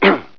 1 channel
clrthrt.wav